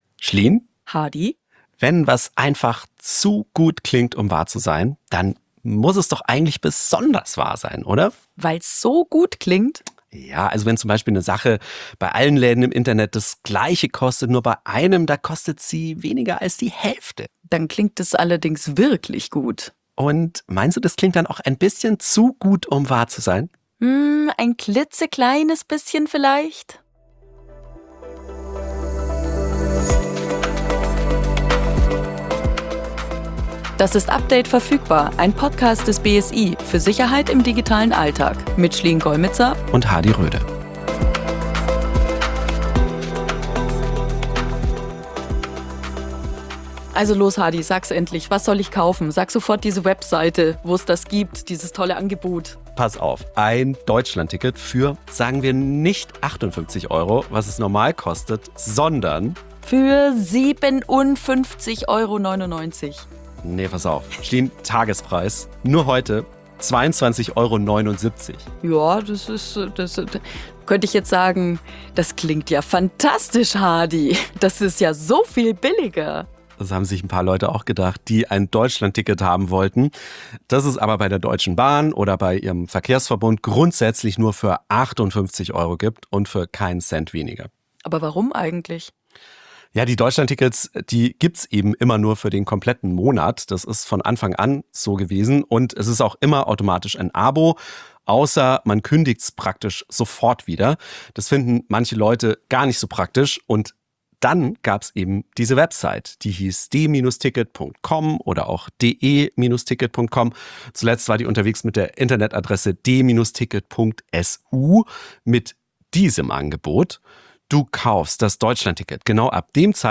Und auch die Polizei haben die beiden befragt: